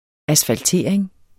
Udtale [ asfalˈteˀɐ̯eŋ ]